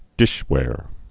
(dĭshwâr)